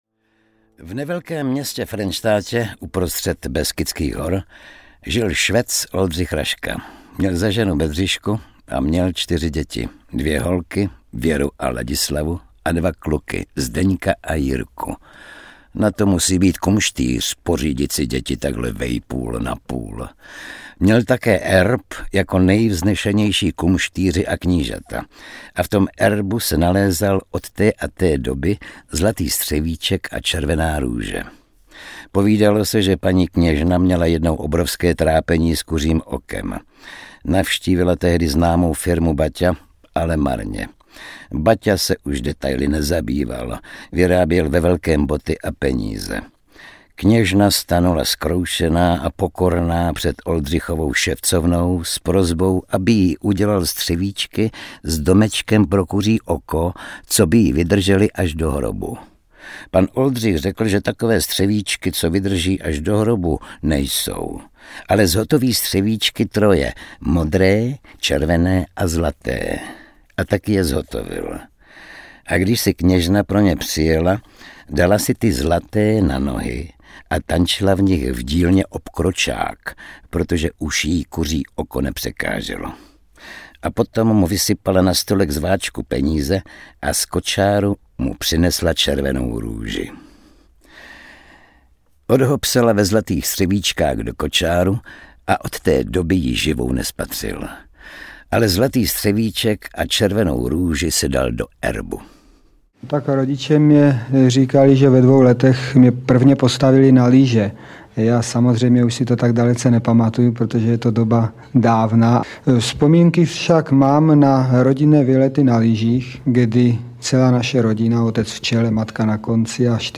Audiobook
Read: Oldřich Kaiser